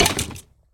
mob / skeleton / hurt1.ogg
hurt1.ogg